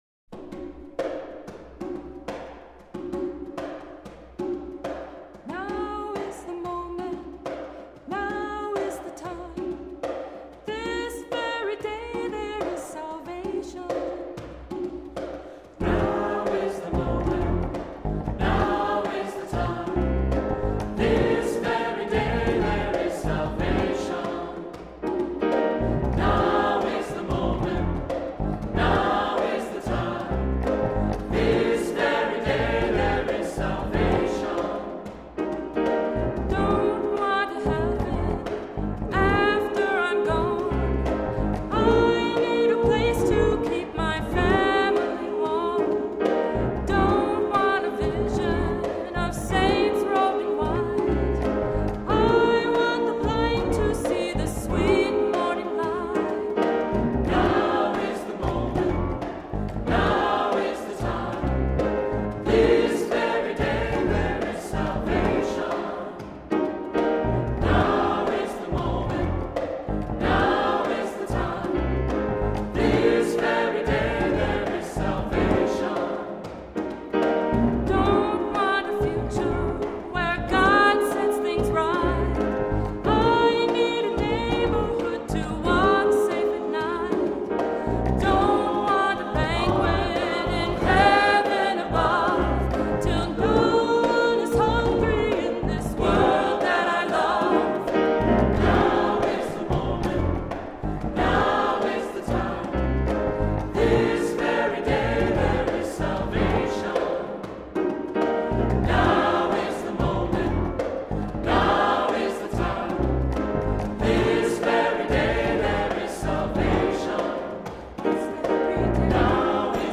Voicing: SAT; Cantor; Assembly